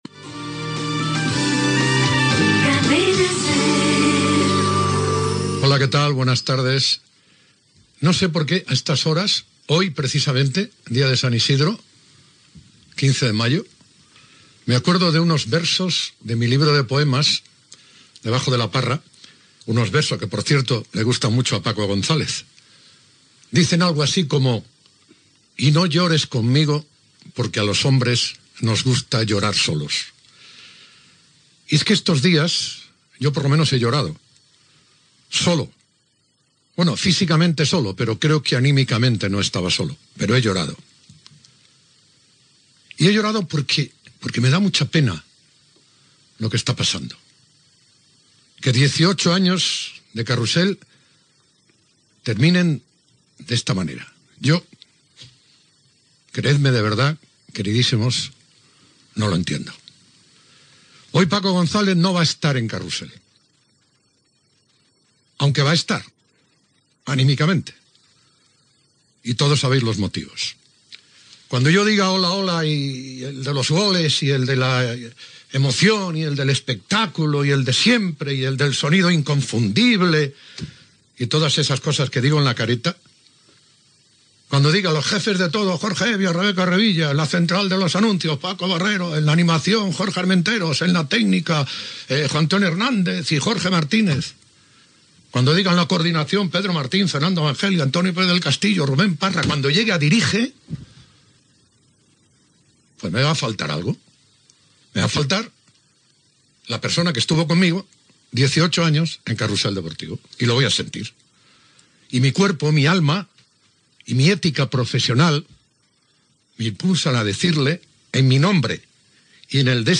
Indicatiu del programa, data i record de Pepe Domingo Castaño a Paco González que ha deixat de presentar el programa per decisió de la direcció de la Cadena SER.
Esportiu